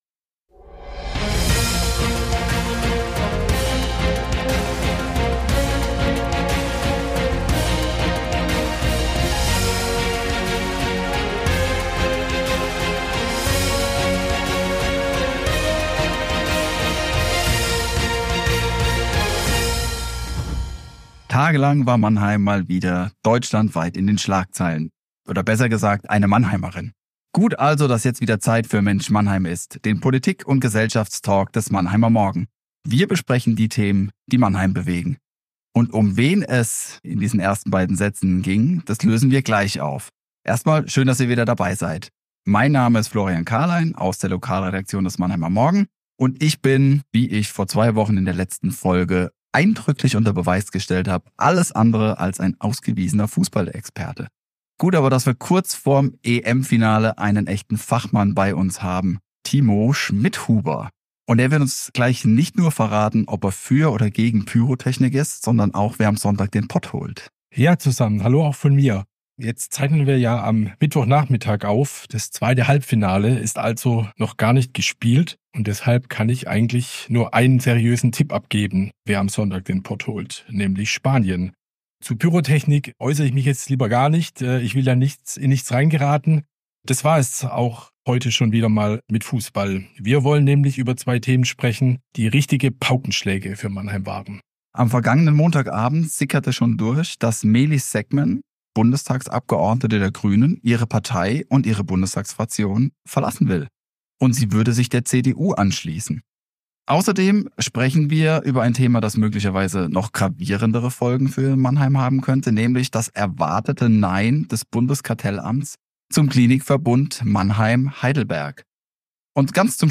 mit zwei Gästen darüber, die ebenfalls einen Parteiwechsel hinter sich haben